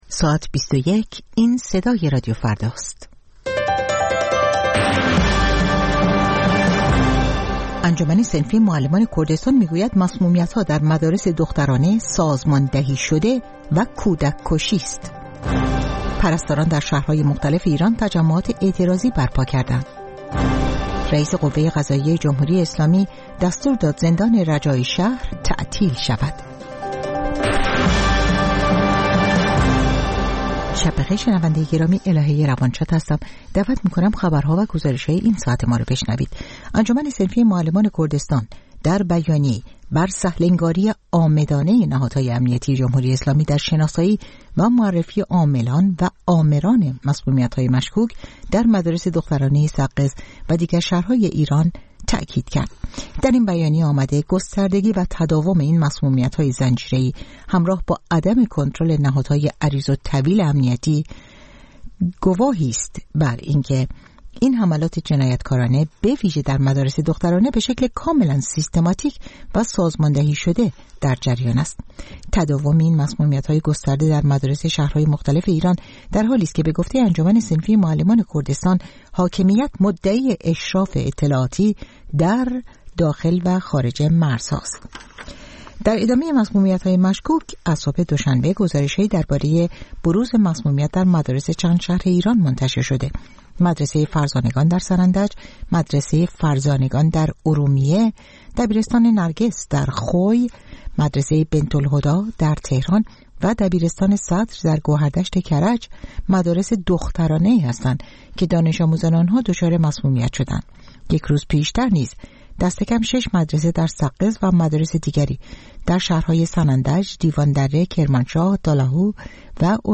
خبرها و گزارش‌ها ۲۱:۰۰